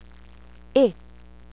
shva At end of syllable: silent.
In middle of syllable: like "a" as in alone